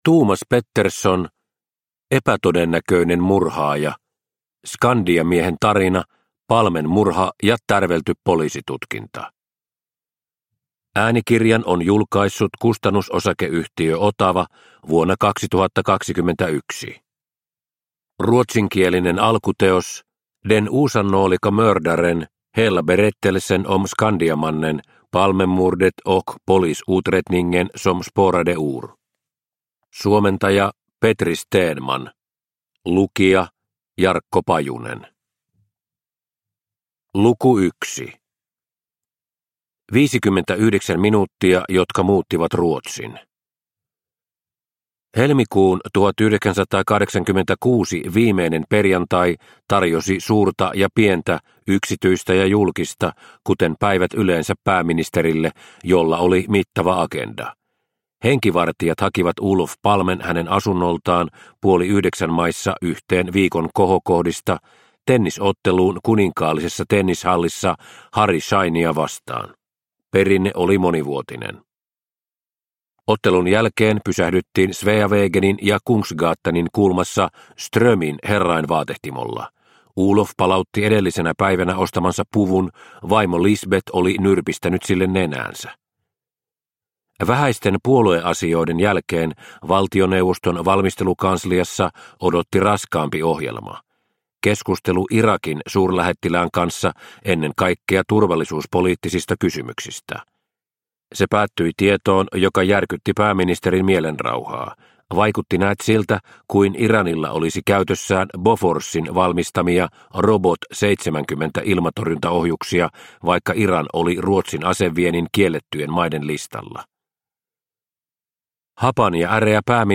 Epätodennäköinen murhaaja – Ljudbok – Laddas ner